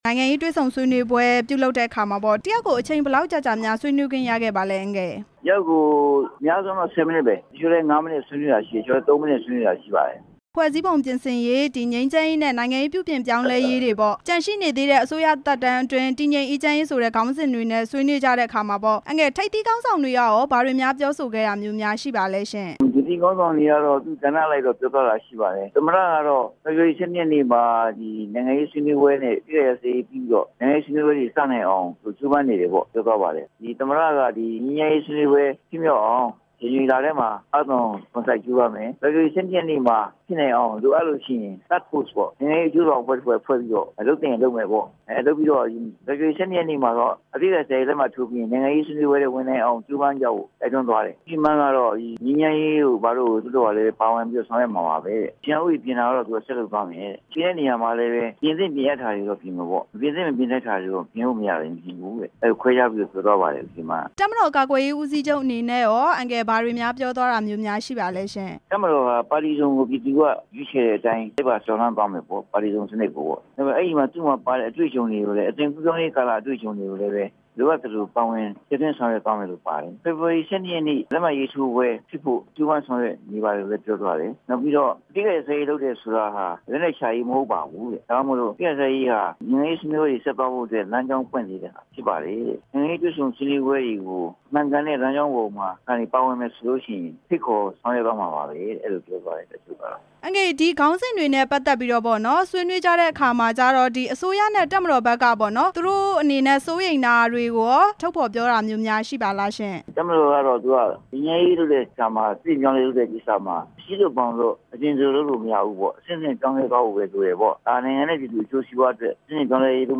အမျိုးသားဒီမိုကရေစီအင်အားစုပါတီ ဥက္ကဌ ဦးခင်မောင်ဆွေနဲ့ မေးမြန်းချက်